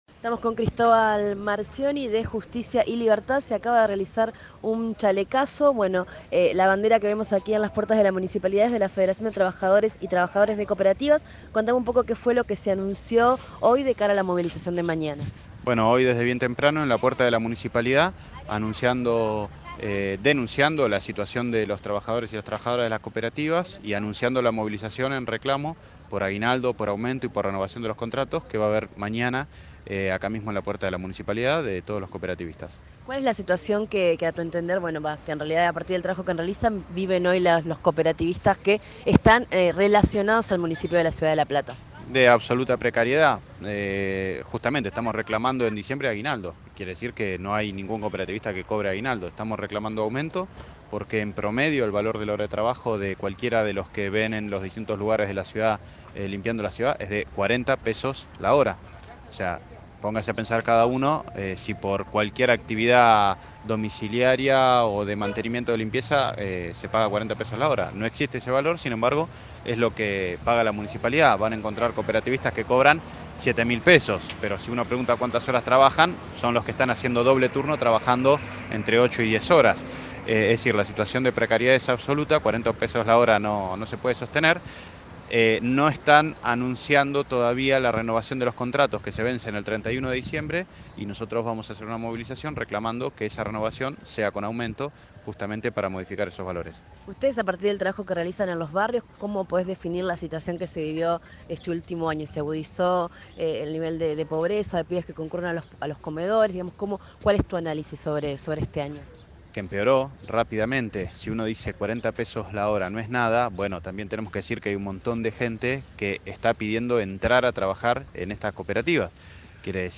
Móvil: